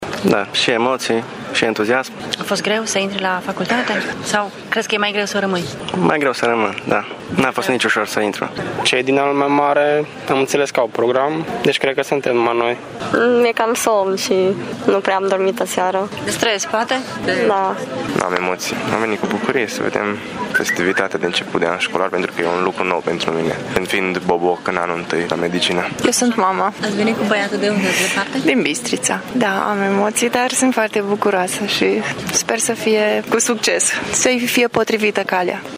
Studenții mediciniști din anii superiori au și început de astăzi cursurile, iar bobocii din anul întâi au asistat cu emoții și entuziasm la festivitatea de deschidere: